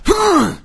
zo_pain1.wav